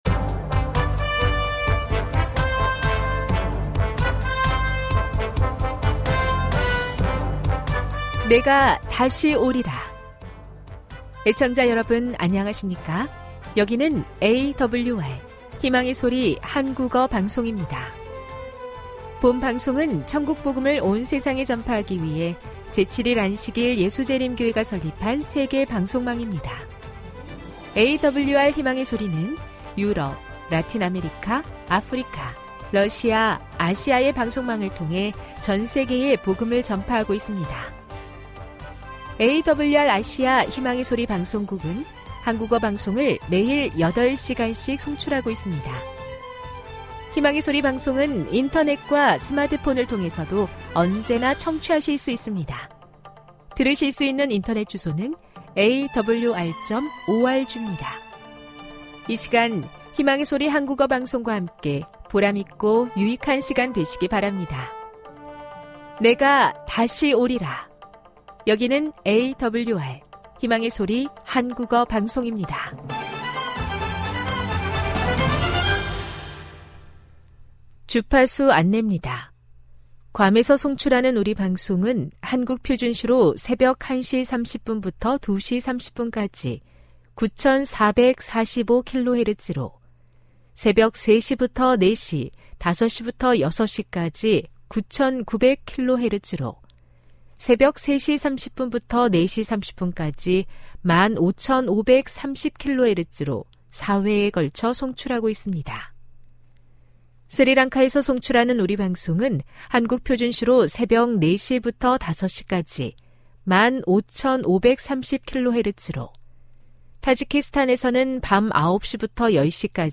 1 설교, 말씀묵상 59:00